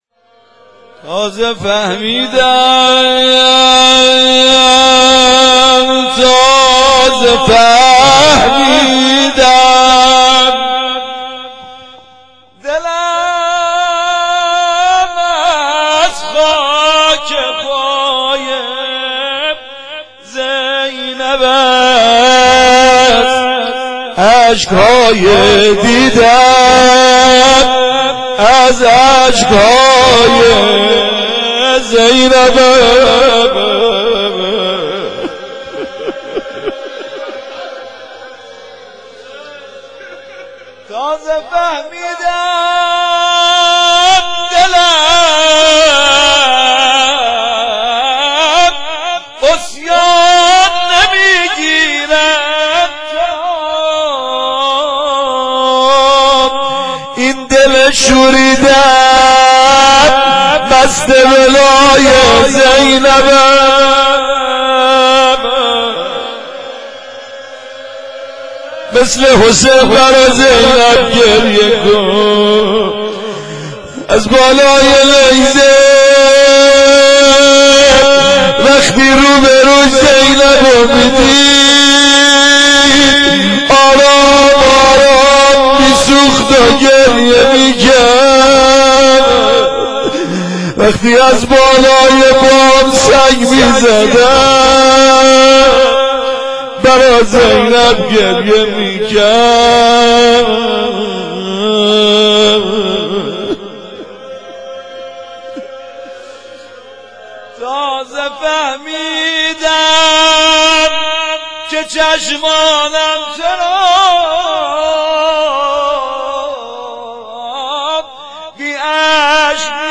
بخش دوم روضه